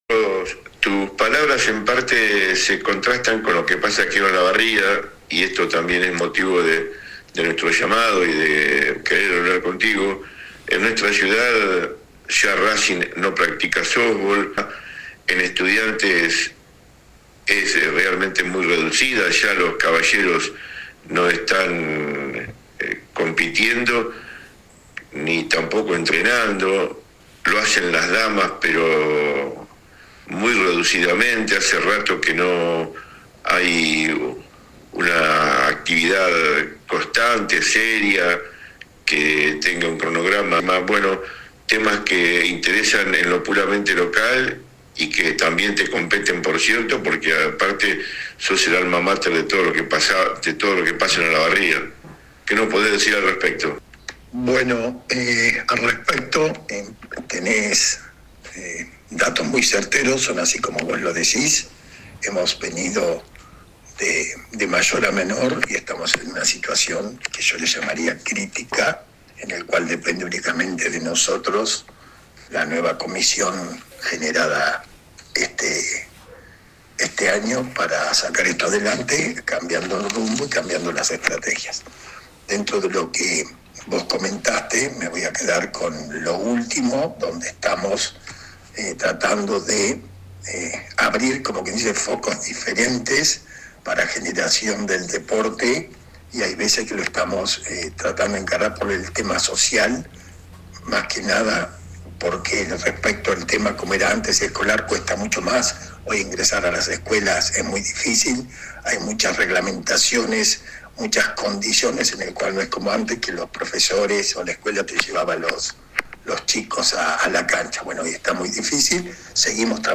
AUDIO DE LA ENTREVISTA ( en tres bloques )